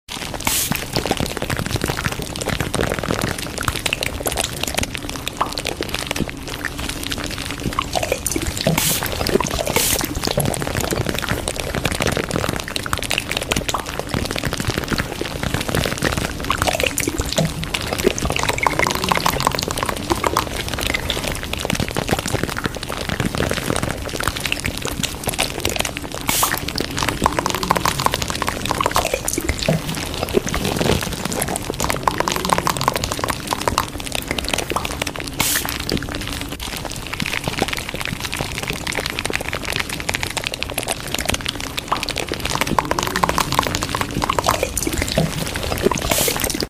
❄cafe sound❄ sound effects free download